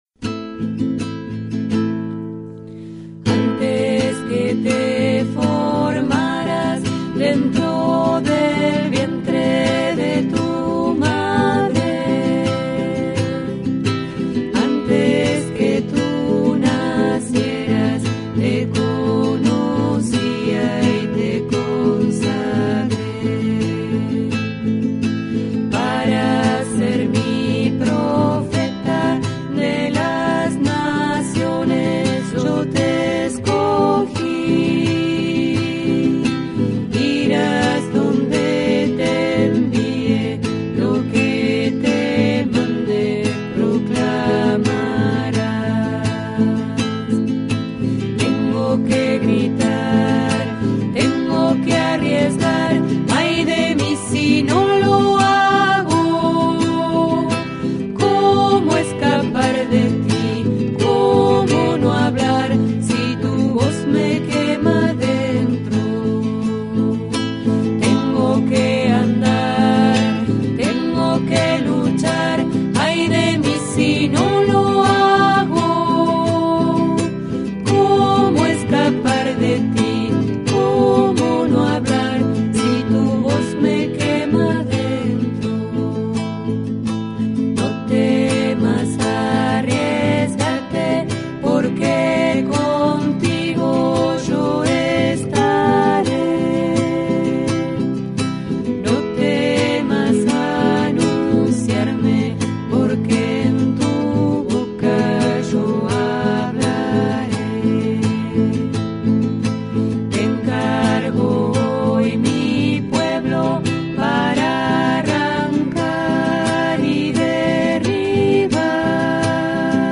29ºDA-C misa.mp3